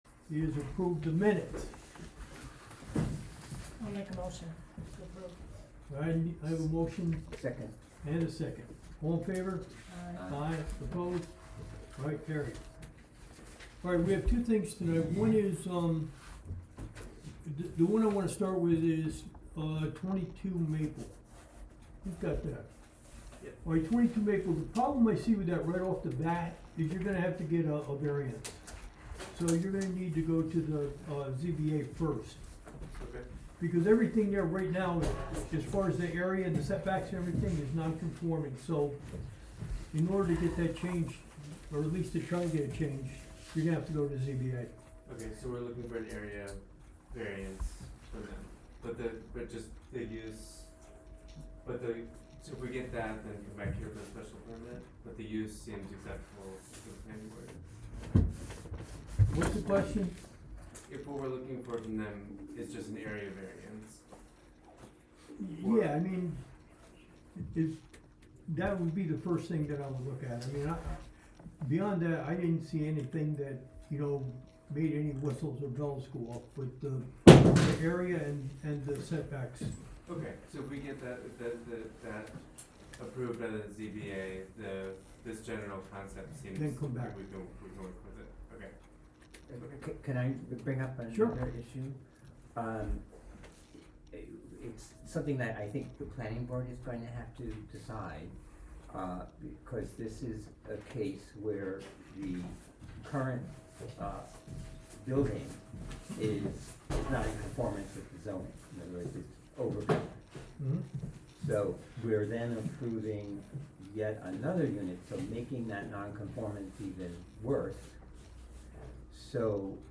Live from the Village of Philmont: Planning Board Meeting (Audio)